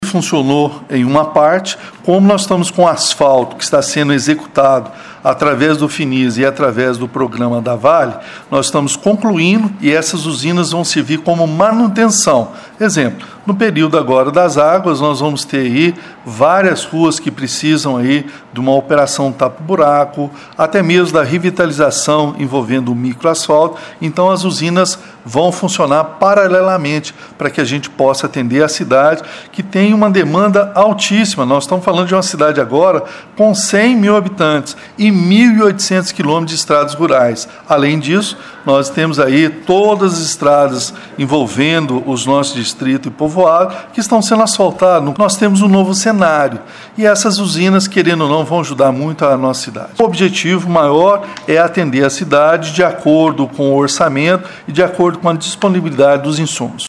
Com isso nós procuramos o prefeito Elias Diniz que garantiu que as usinas estão funcionando bem. Também explicou que elas serão utilizadas de forma paralela aos trabalhos de pavimentação em andamento na cidade que acontecem através de contratos.